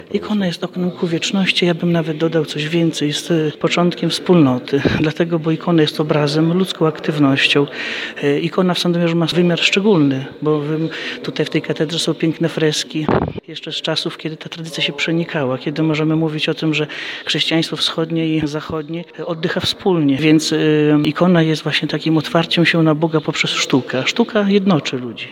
Spotkanie ekumeniczne wieńczące obchody Tygodnia Modlitw o Jedność Chrześcijan w diecezji sandomierskiej odbyło się w bazylice katedralnej w Sandomierzu.